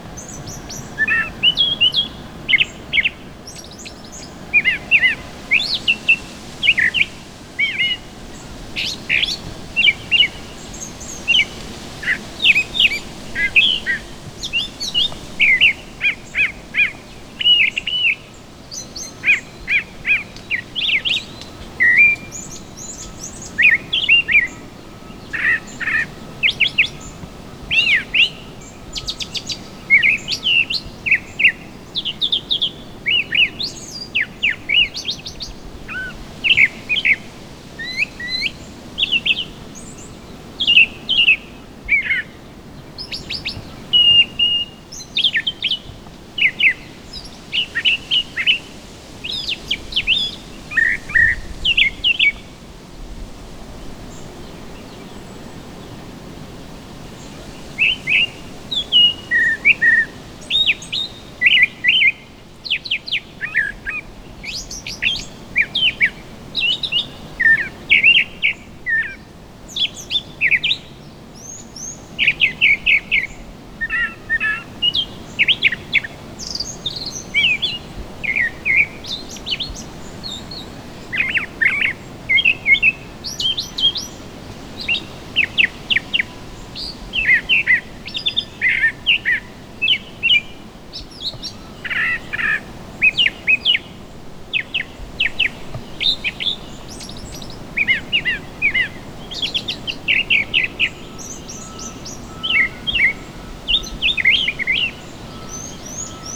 The Spring Soundscape
Males, the ones who sing, usually do so from a high perch, (while photographers, the ones who record, usually do so with a super telephoto).
Brown Thrasher singing
That’s quite a repertoire—or to put it another way, that’s quite a playlist. Listen for the paired phrases, the regular pauses, the musical quality.
brown-thrasher1.wav